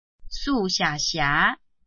臺灣客語拼音學習網-客語聽讀拼-南四縣腔-開尾韻
拼音查詢：【南四縣腔】xia ~請點選不同聲調拼音聽聽看!(例字漢字部分屬參考性質)